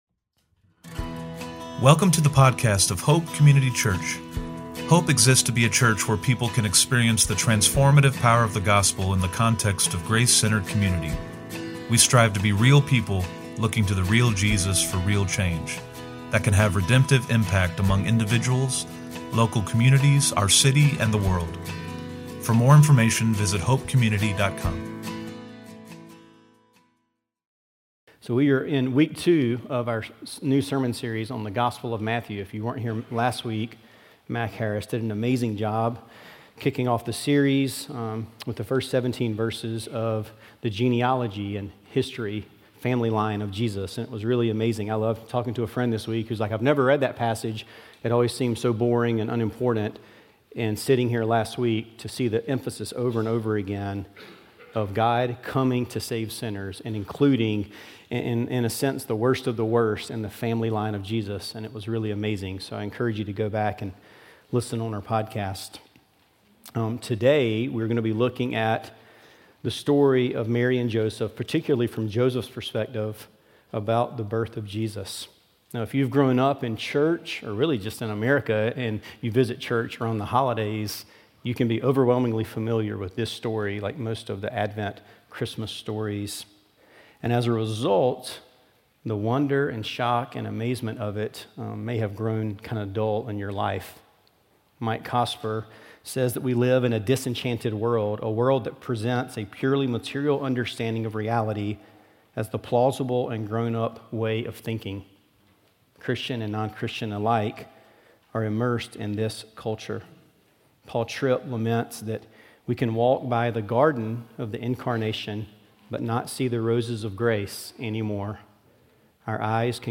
Olde Providence Congregation - Hope Community Church
OP-Sermon-12.7.25.mp3